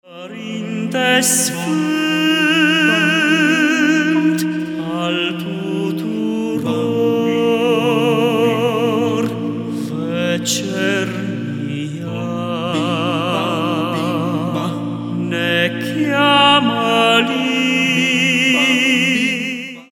four male voices
Byzantine Orthodox Songs